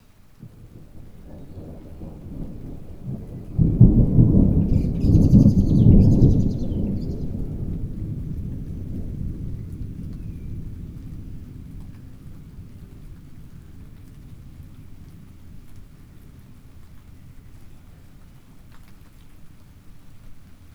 thunder-and-the-bird.wav